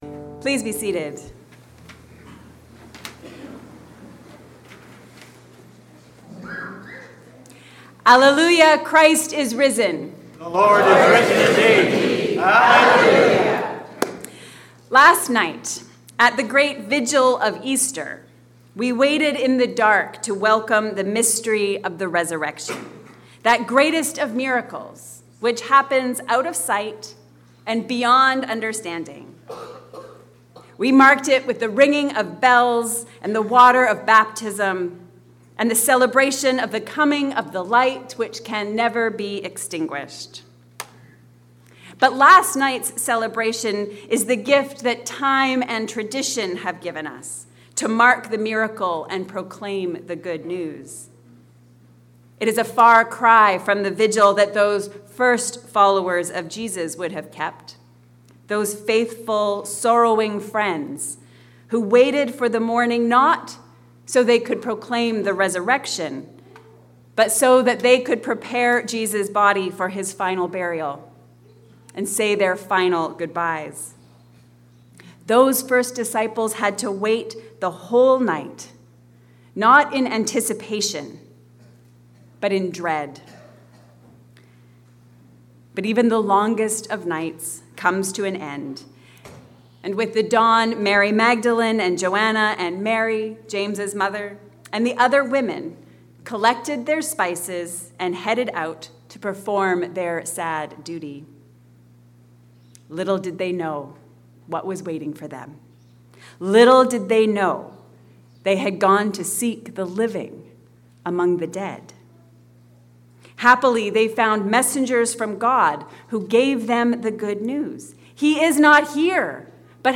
Resurrection Reminders. A sermon for Easter morning